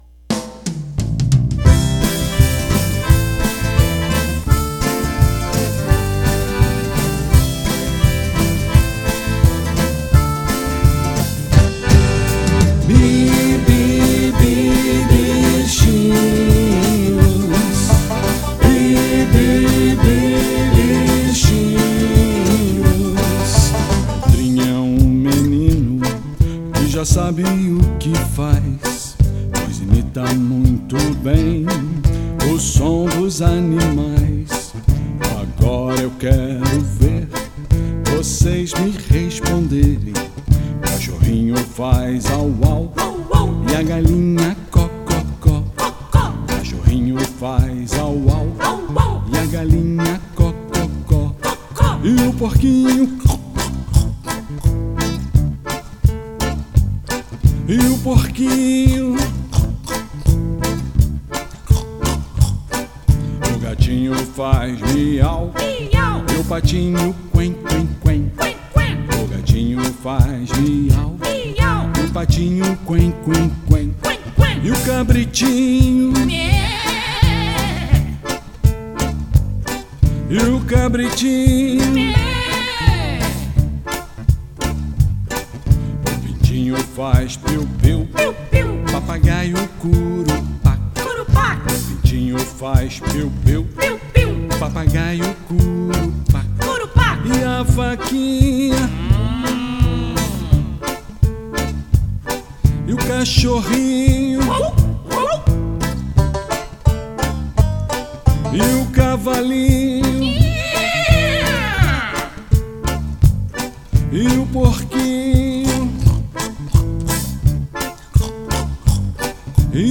EstiloInstrumental